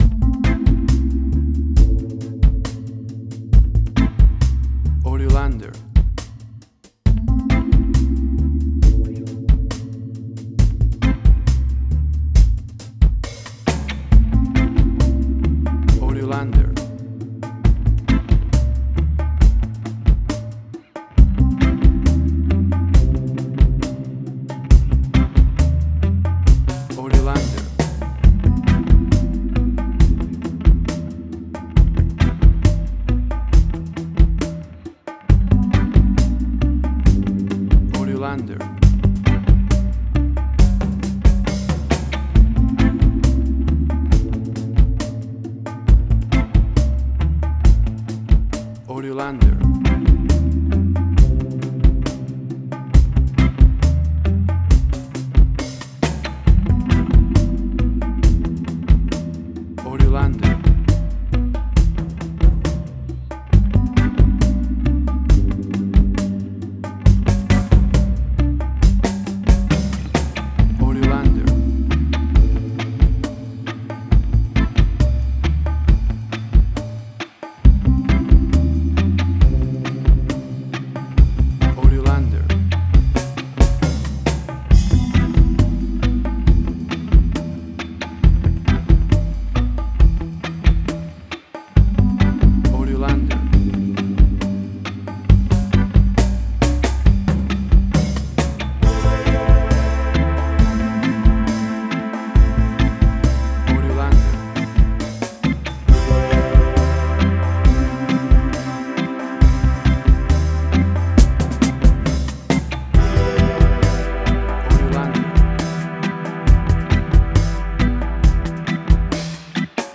Reggae caribbean Dub Roots
Tempo (BPM): 68